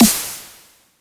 Do We Really_Snare.wav